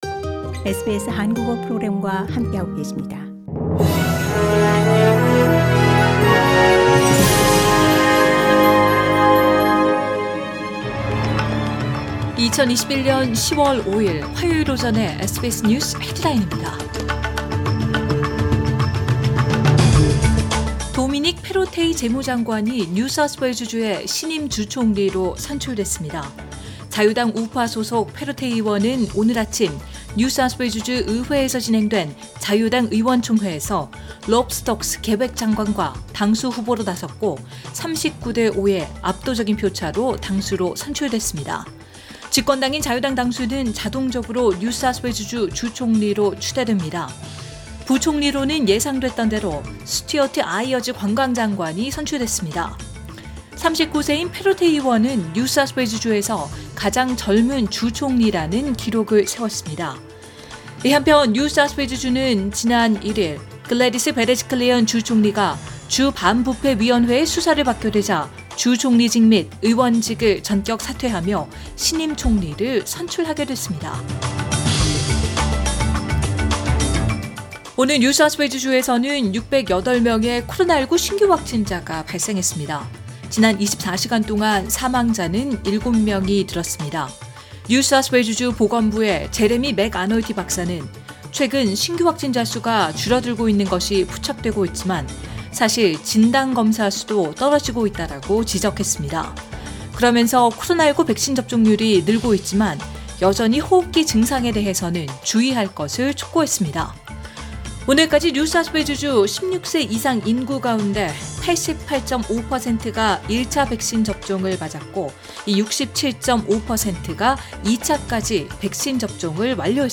2021년 10월 5일 화요일 오전의 SBS 뉴스 헤드라인입니다.